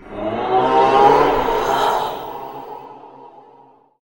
shout.ogg